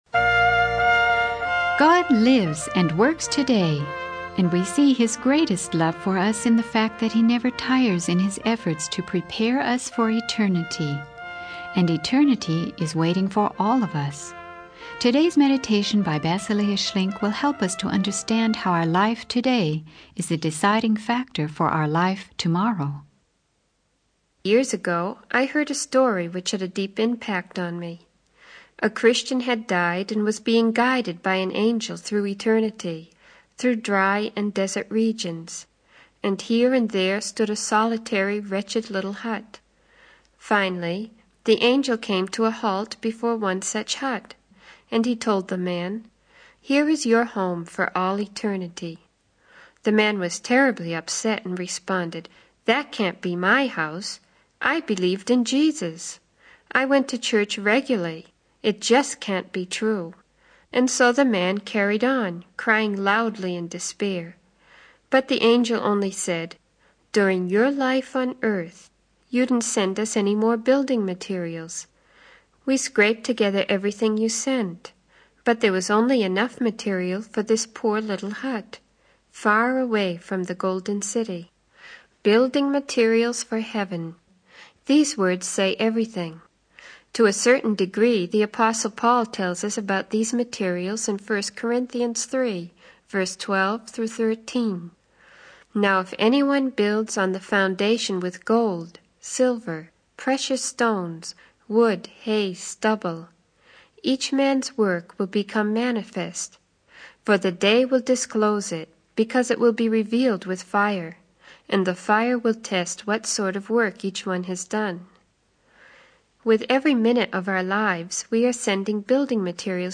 In this sermon, the preacher tells a story about a Christian who dies and is guided by an angel through eternity.